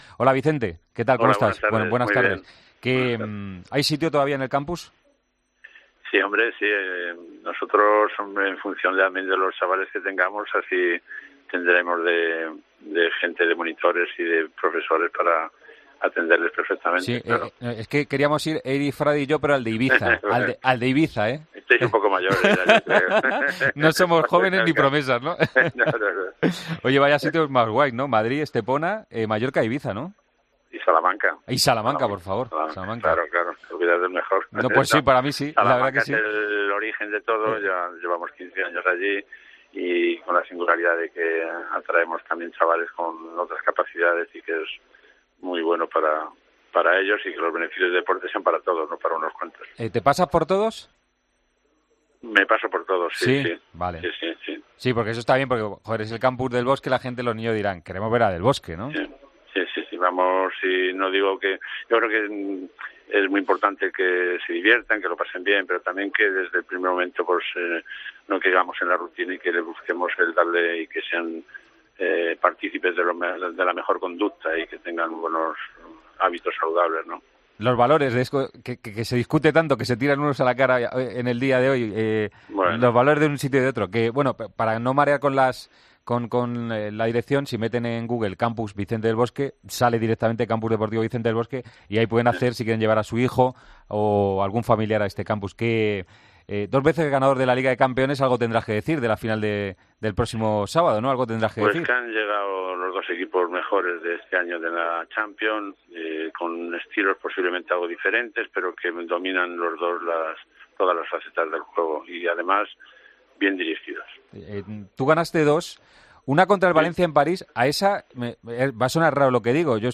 Entrevista en Deportes COPE